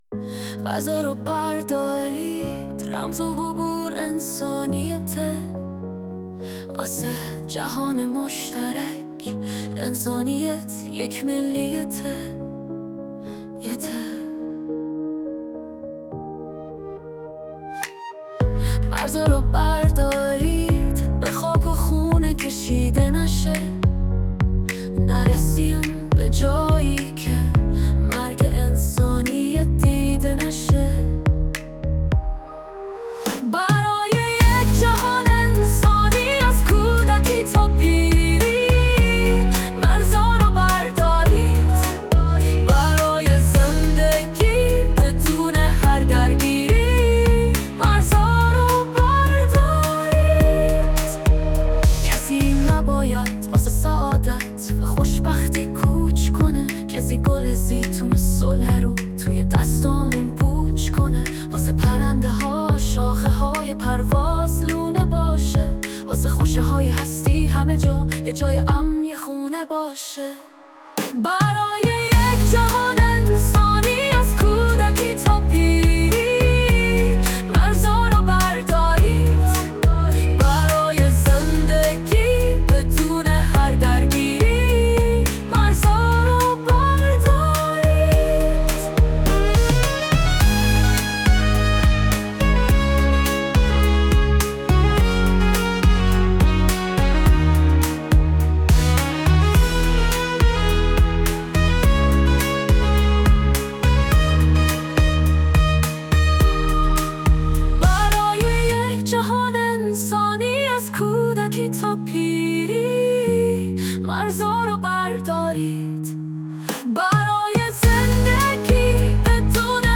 پاپ
آهنگ با صدای زن
اهنگ ایرانی